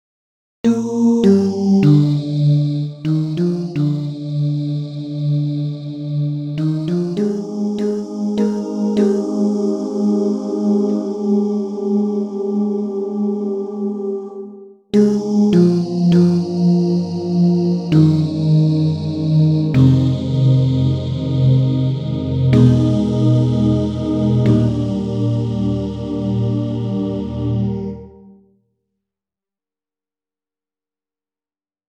Key written in: E♭ Major